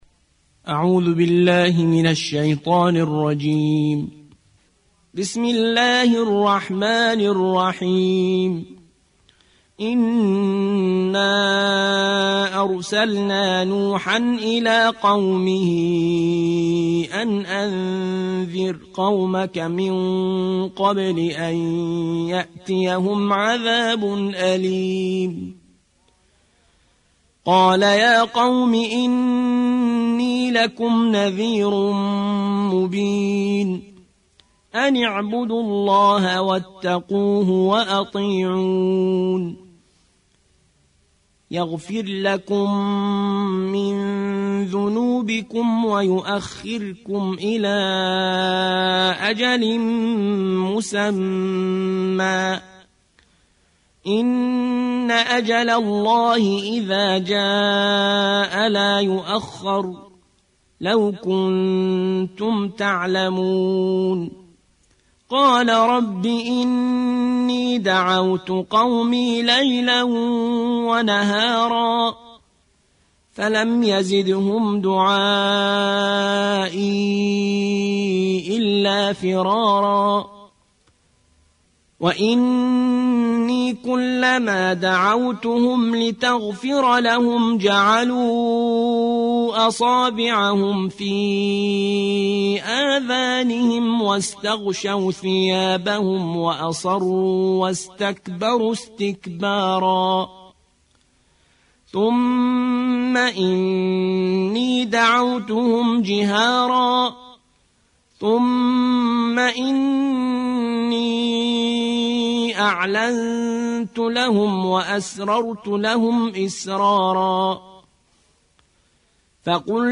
71. سورة نوح / القارئ